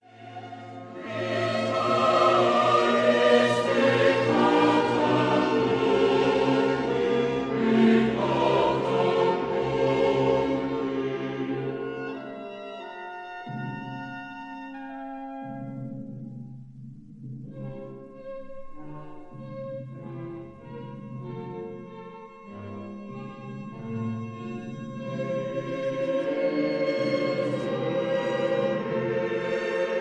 soprano
contralto
tenor
bass